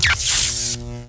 SFX电流电击音效下载
SFX音效